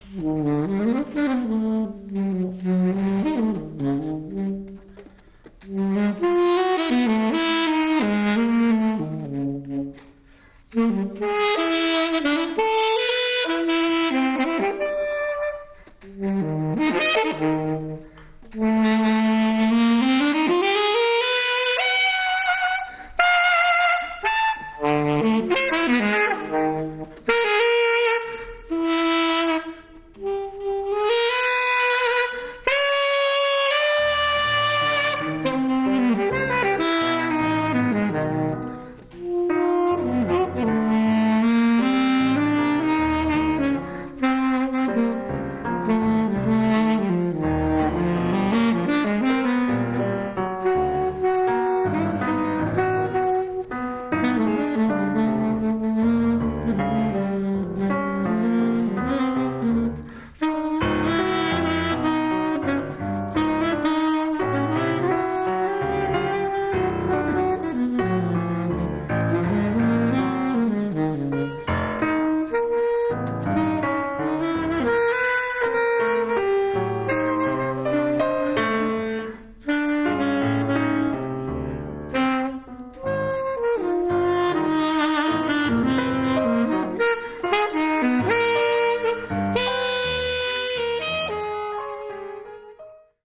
soprano sax, clarinet, flute, alto sax
piano